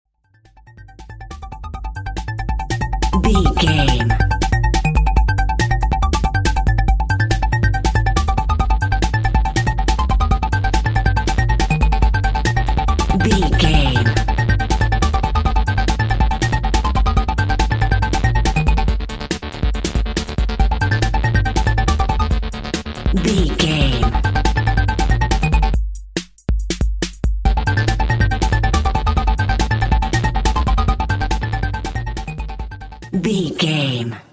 Aeolian/Minor
electronic
techno
trance
industrial
drone
glitch
synth lead
synth bass
synth drums